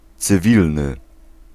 Ääntäminen
IPA : /sɪˈvɪljən/